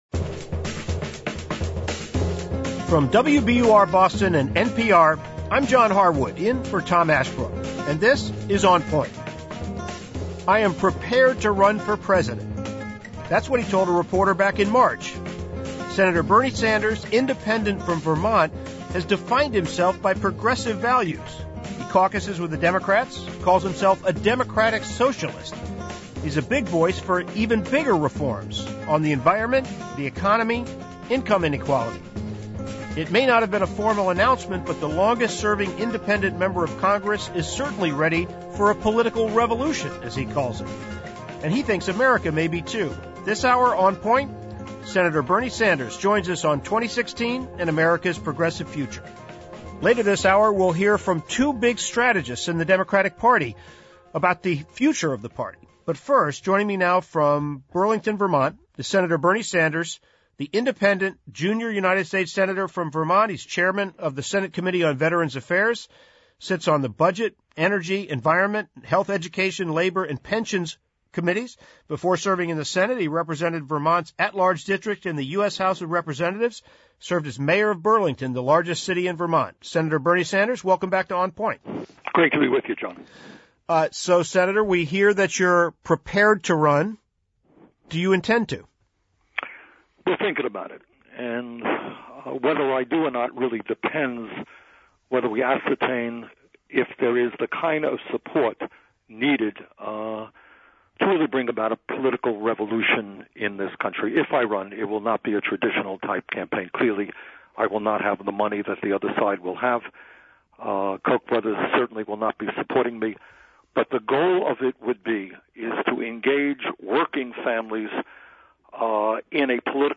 In this interview Monday on NPR's "On Point," Sen. Bernie Sanders (I-Vt.) laid out his argument for a potential presidential bid